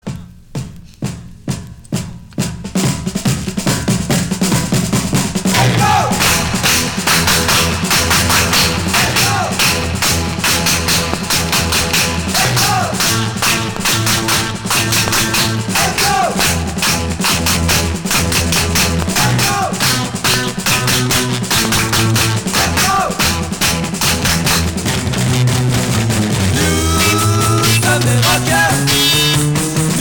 Rock garage